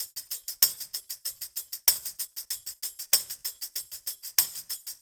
Track 14 - Tambourine.wav